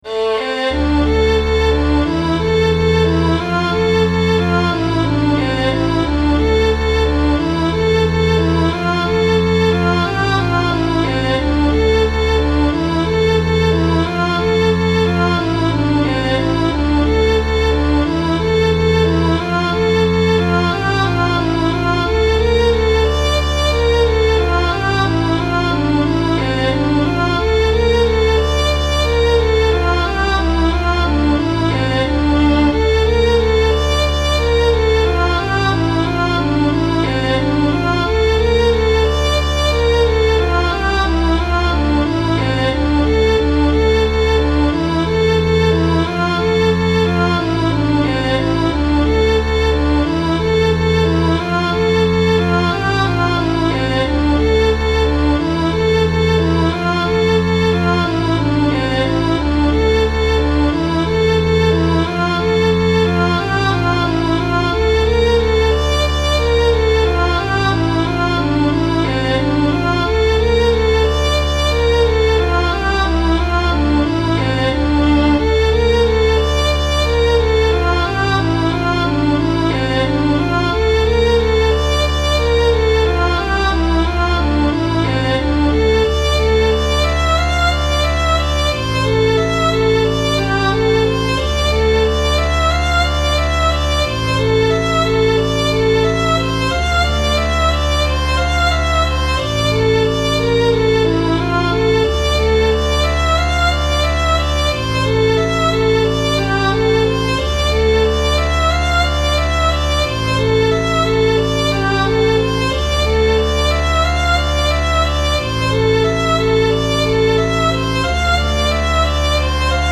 This one is a little dark ...
What I wrote today is admittedly a little on the dark side.  "Walking on the Edge" is a musical interpretation of the mental processes one takes when considering two options, neither of which are particularly good.
I was skeptical when you started all this, not expecting much, but you're actually creating some very nice tunes that are becoming more tonally and rhythmically complex as you go along.
It does sound like a video game soundtrack, right?